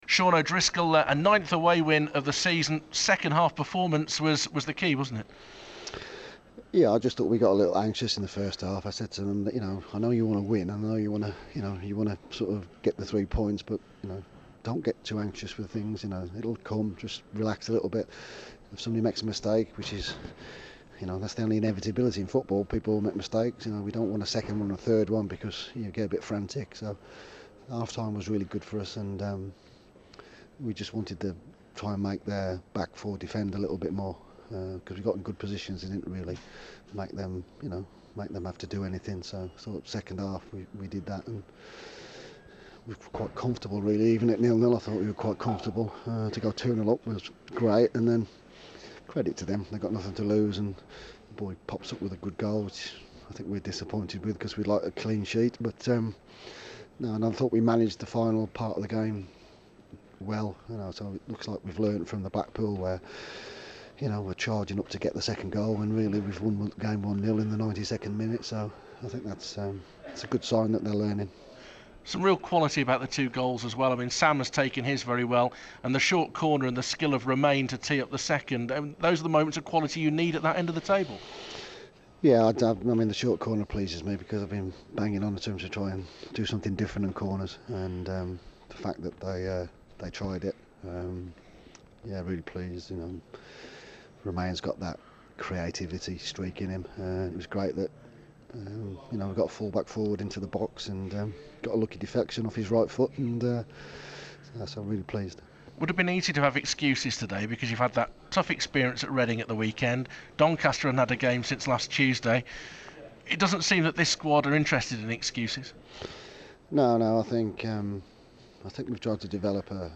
speaks to BBC WM after the 2-1 away win at Doncaster in League One